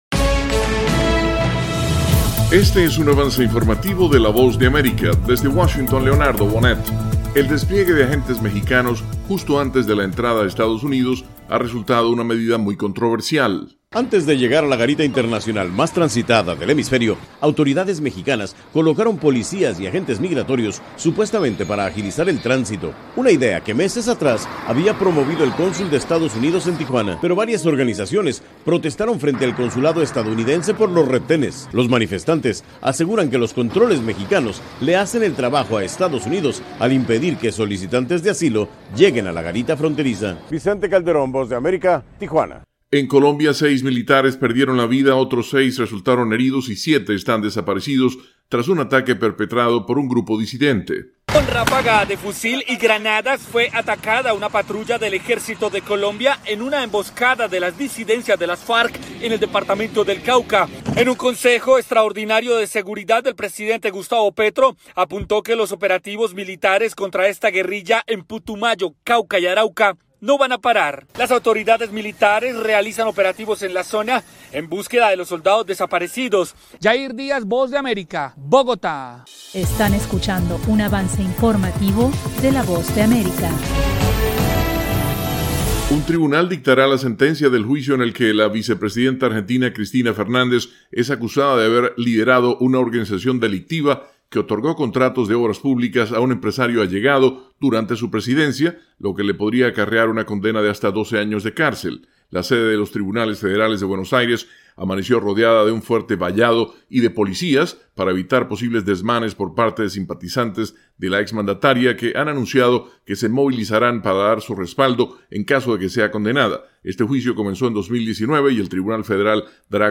Avance Informativo
El siguiente es un avance informativo presentado por la Voz de América, desde Washington